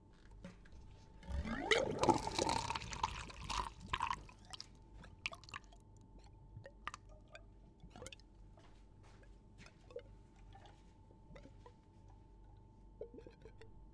办公室的声音 " 服务水
描述：在纸盆上供应水
标签： 办公室 服务 造纸
声道立体声